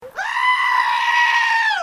Screaming Sheep